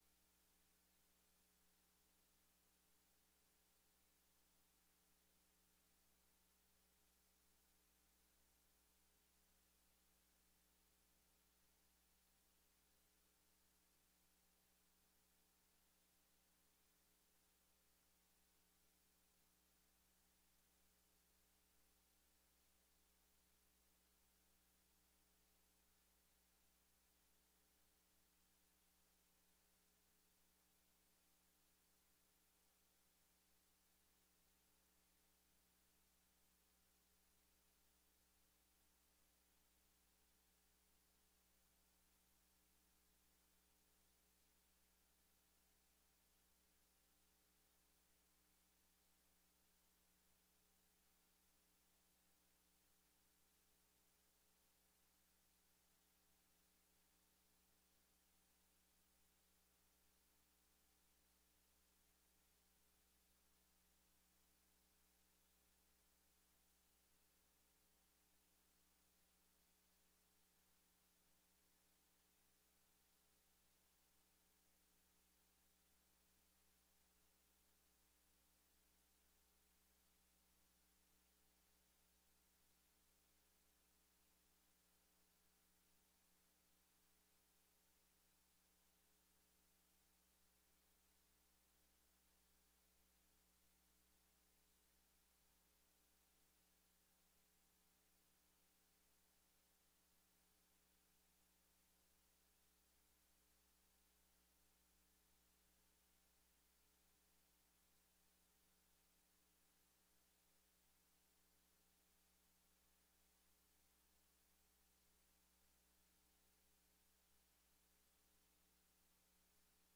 A Podcast collection of sermons and more from Celebrate Church in Knoxville, Iowa.